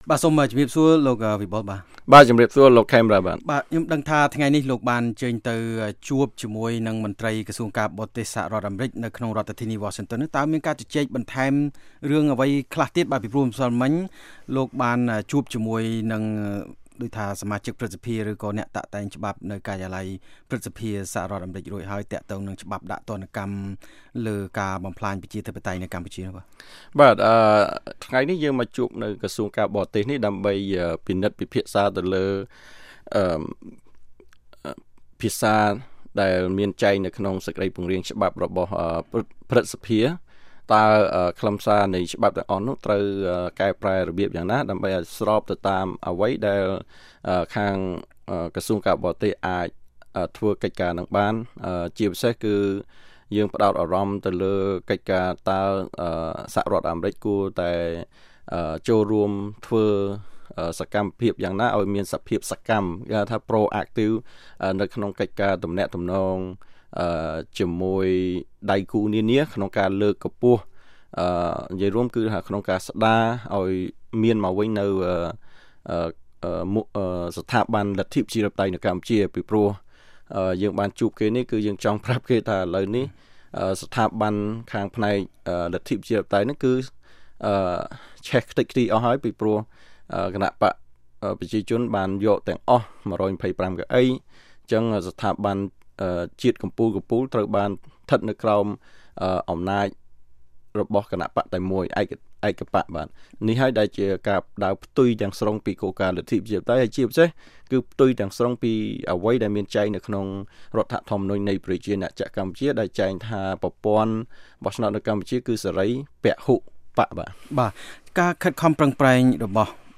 ធ្វើកិច្ចសម្ភាន៍អំពីរឿងនេះ